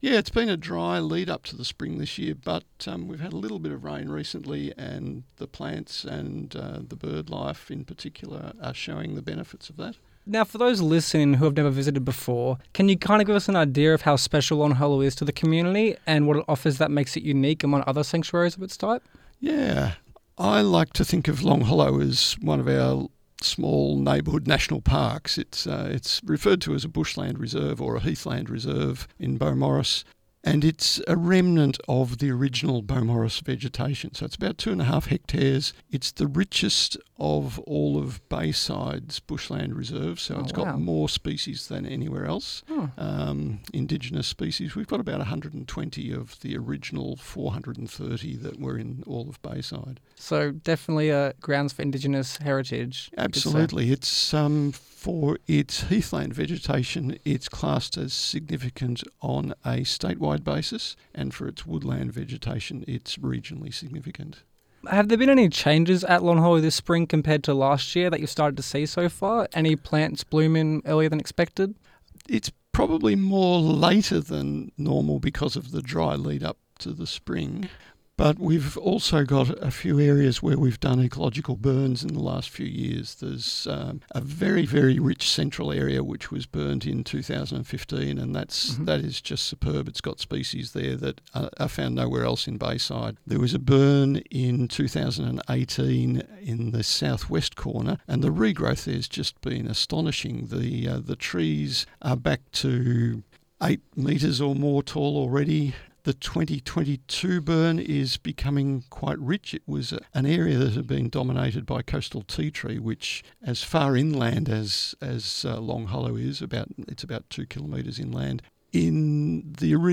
Long-Hollow-Interview-Edited.mp3